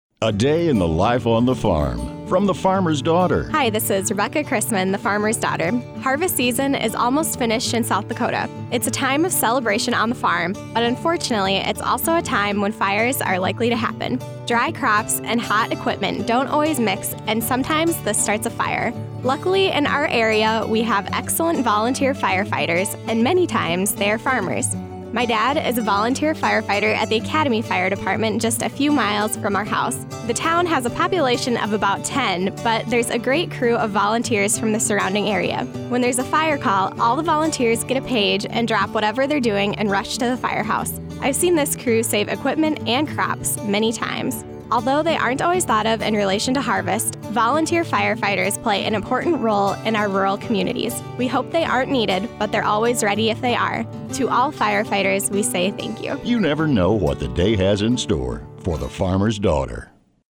Listen to this week's radio segment here! 11.30.15_FD_Firefighters.mp3 Comments Please enable JavaScript to view the comments powered by Disqus. blog comments powered by Disqus